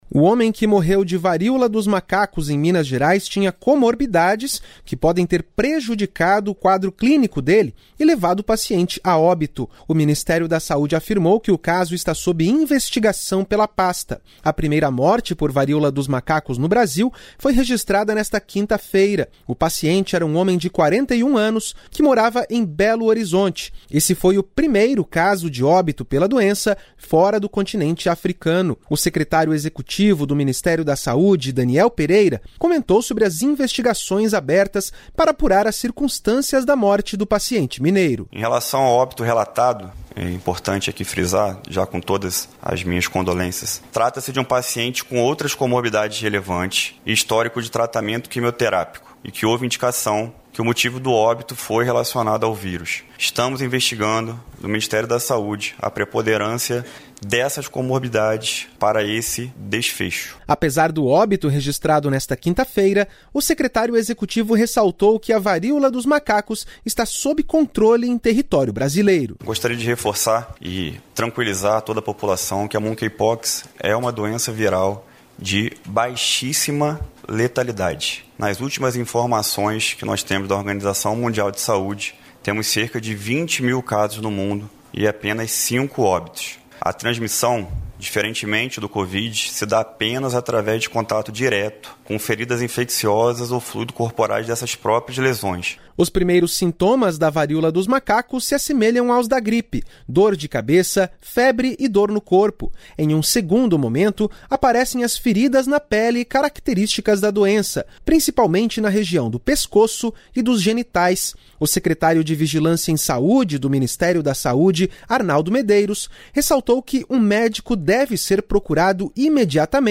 O secretário de Vigilância em Saúde do Ministério da Saúde, Arnaldo Medeiros, ressaltou que um médico deve ser procurado imediatamente em caso de suspeita de infecção.